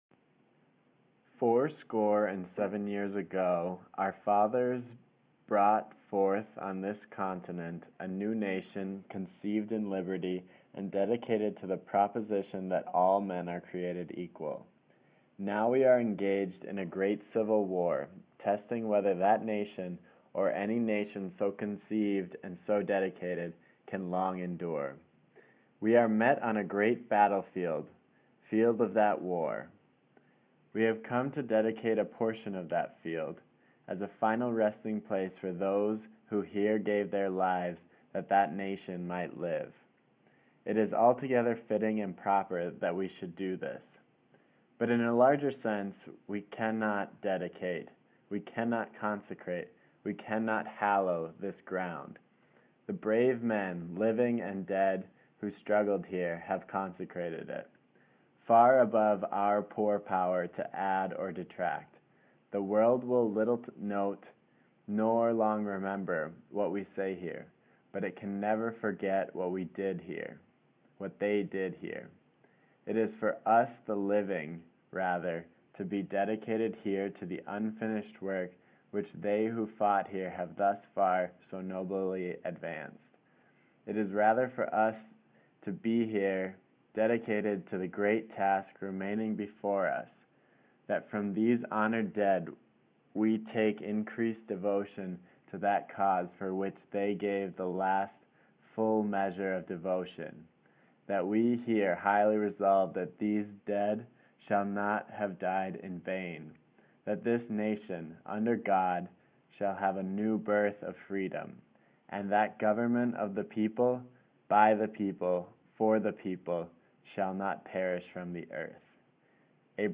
samples_audio-files_05-gettysburg-address-2min.wav